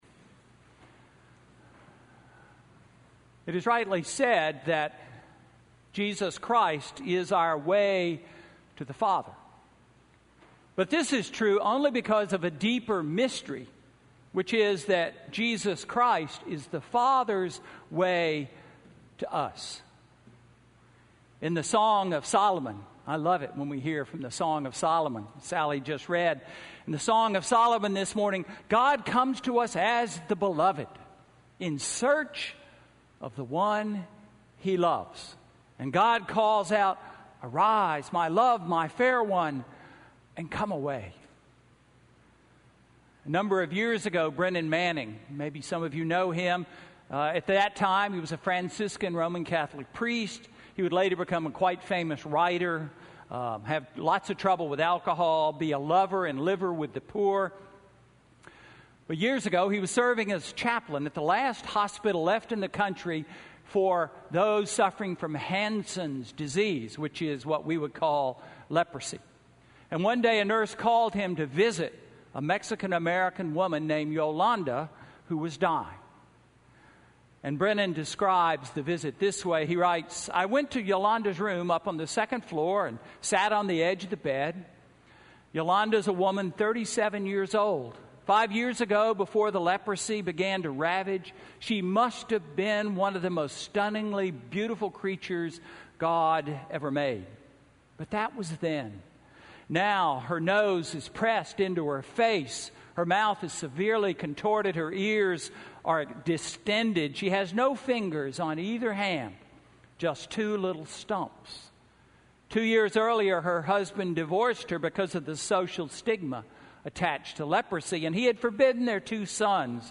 Sermon–August 30, 2015